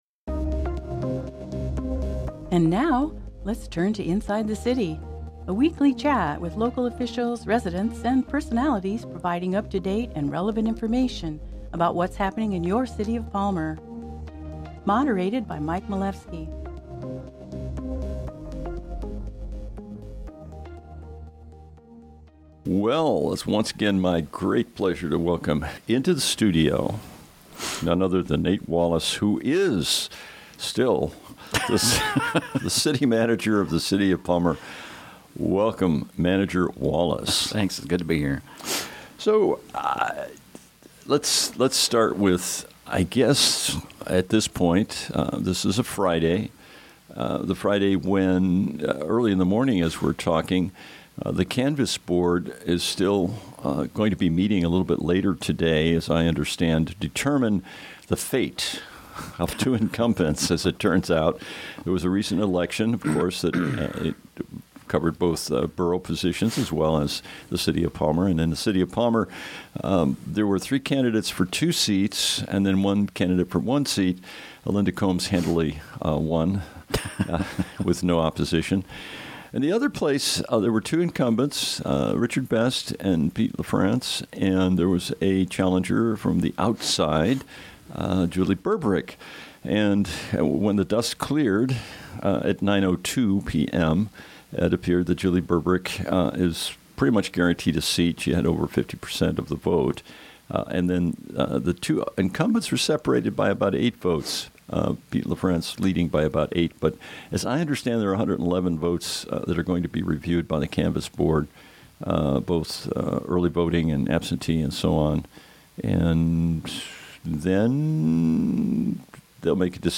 Discussions and interviews with employees and administrators from the city of Palmer